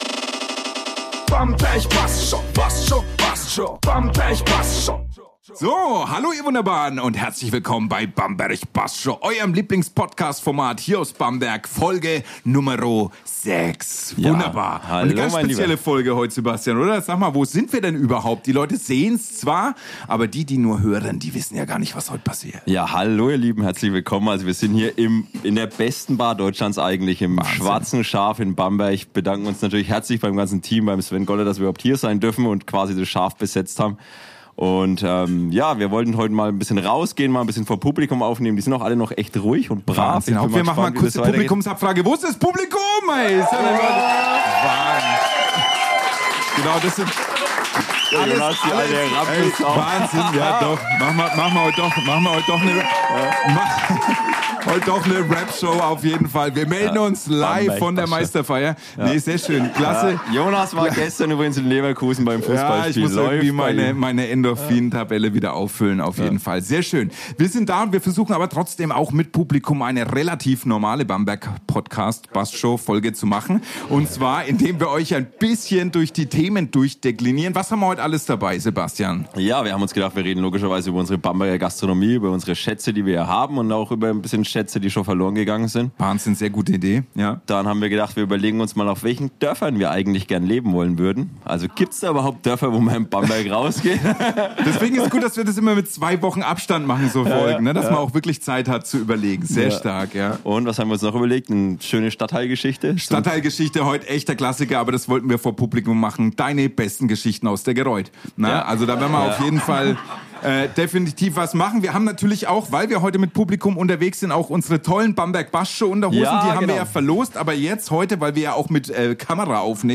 Vor Live-Publikum wird über Bamberger Gastro-Schätze, vergessene Kneipen und die besten Dörfer zum Leben geplaudert. Dazu gibt es spannende Anekdoten, witzige Erinnerungen und natürlich ein kultiges Gewinnspiel.